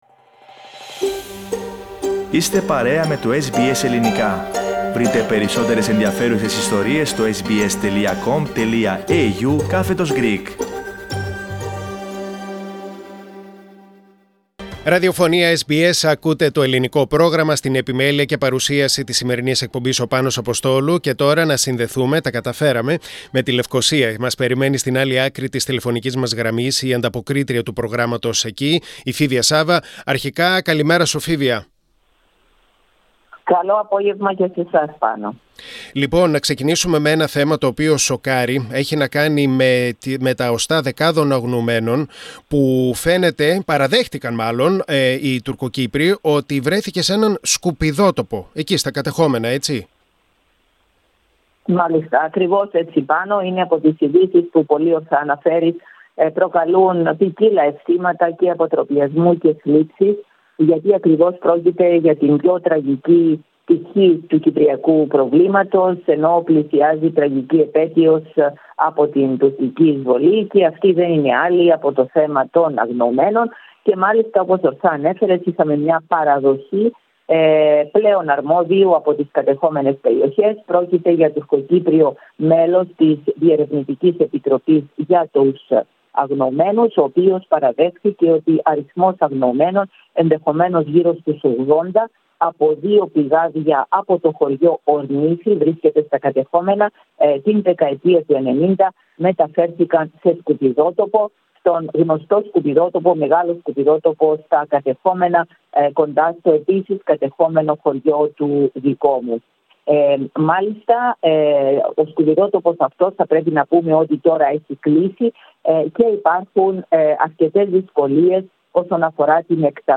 Ειδήσεις και νέα από την Λευκωσία στην εβδομαδιαία ανταπόκριση από την Κύπρο.